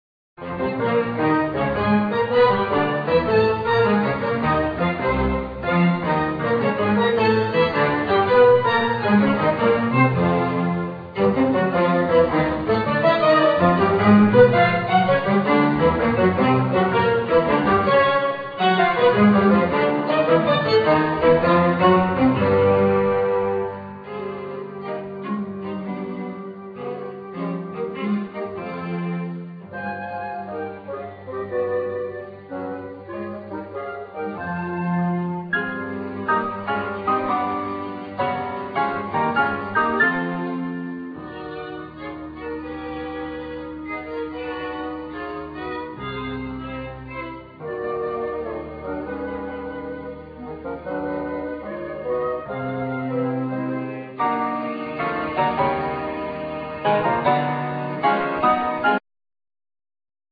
Vocals
Flute,Sax
Clarinet
Basoon
Piano,Flute
Violin,Trombone
Viola
Cello
Double bass